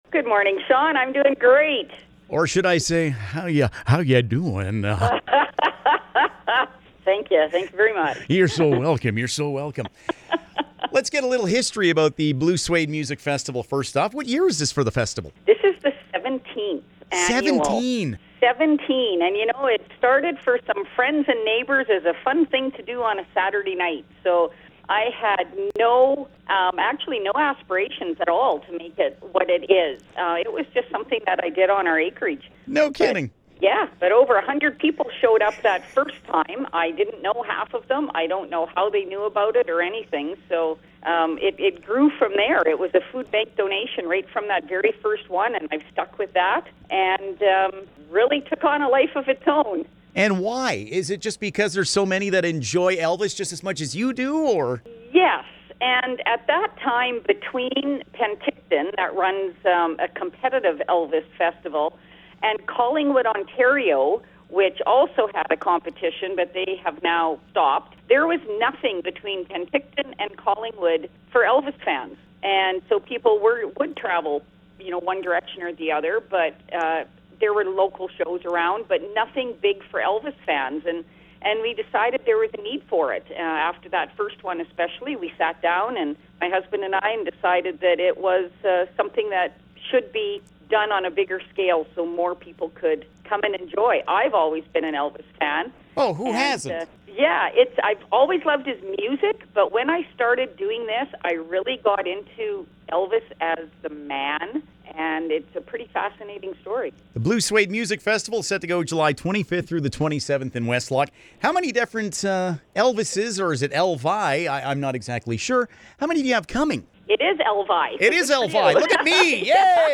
blue-suede-interview.mp3